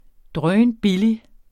drønbillig adjektiv Bøjning -t, -e Udtale [ ˈdʁœːnˈbili ] Betydninger meget billig SPROGBRUG uformelt Synonymer tæskebillig dødbillig Rapportér et problem fra Den Danske Ordbog Den Danske Ordbog .